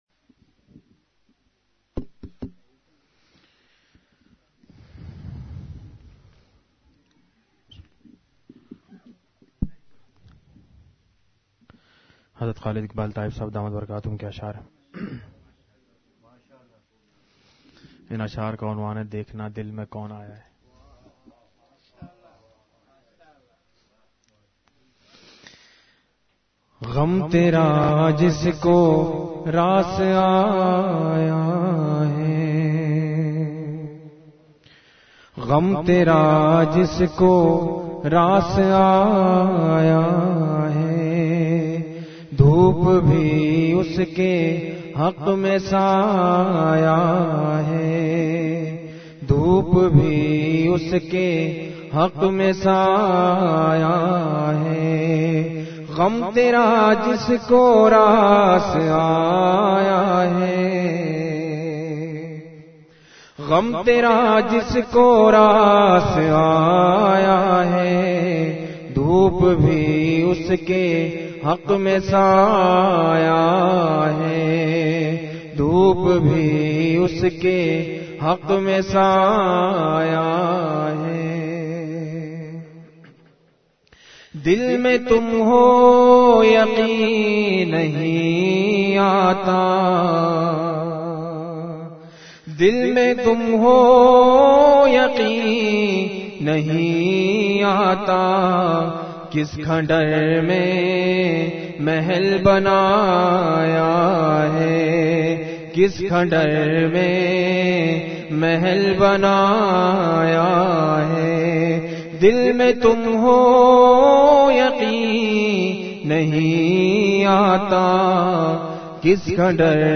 اصلاحی مجلس